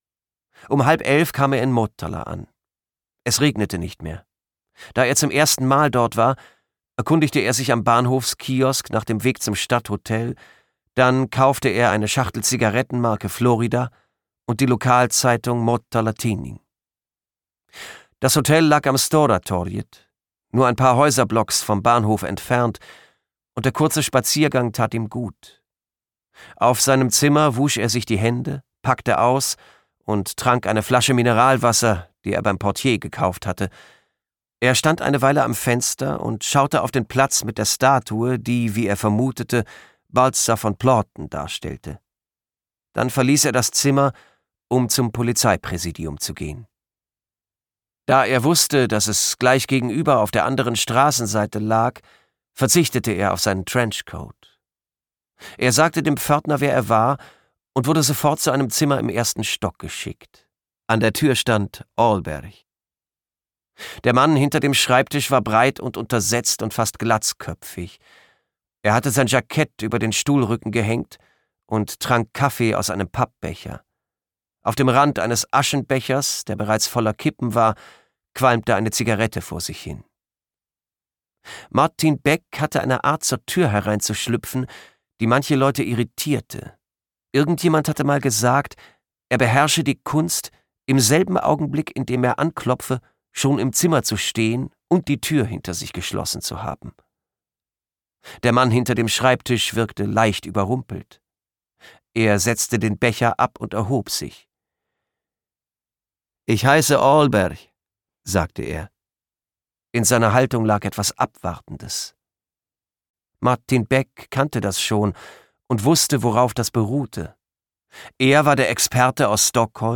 Die Tote im Götakanal: Ein Kommissar-Beck-Roman - Maj Sjöwall, Per Wahlöö | argon hörbuch
Gekürzt Autorisierte, d.h. von Autor:innen und / oder Verlagen freigegebene, bearbeitete Fassung.